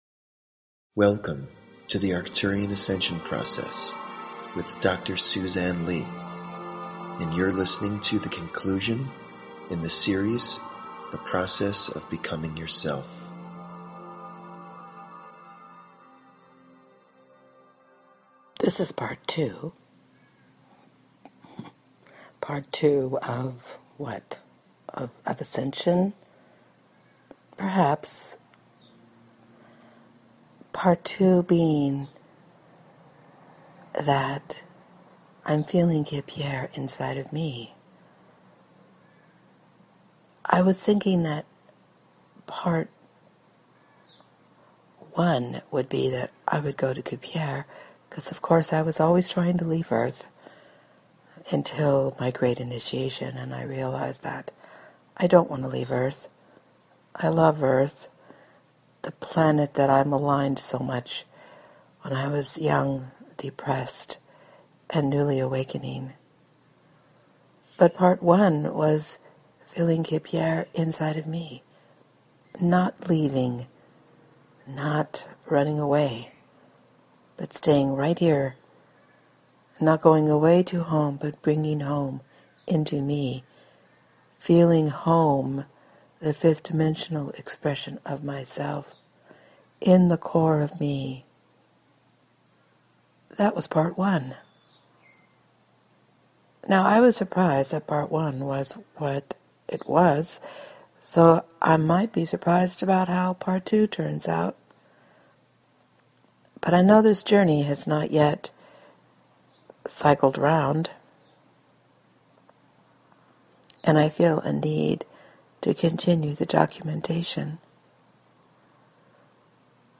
Talk Show Episode, Audio Podcast, Arcturian_Ascension_Process and Courtesy of BBS Radio on , show guests , about , categorized as